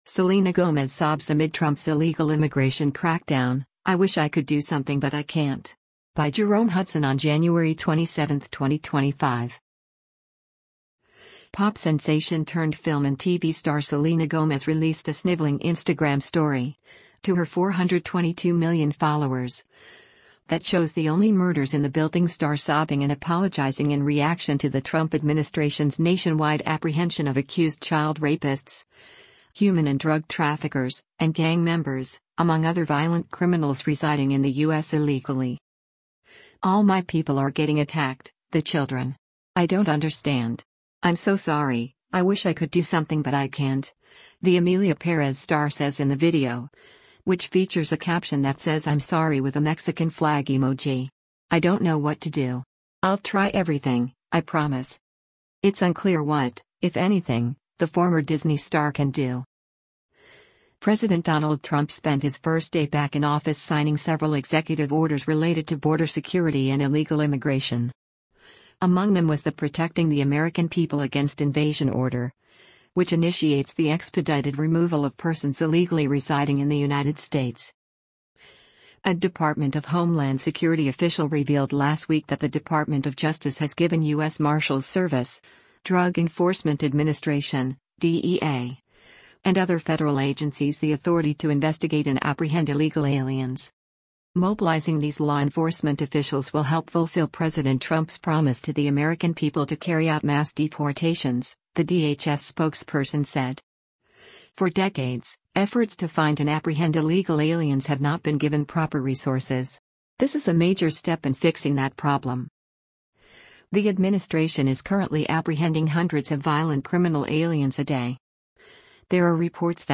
Selena Gomez Sobs Amid Trump’s Illegal Immigration Crackdown: ‘I Wish I Could Do Something but I Can’t’
Pop sensation-turned film and TV star Selena Gomez released a sniveling Instagram story, to her 422 million followers, that shows the Only Murders in the Building star sobbing and apologizing in reaction to the Trump administration’s nationwide apprehension of accused child rapists, human and drug traffickers, and gang members, among other violent criminals residing in the U.S. illegally.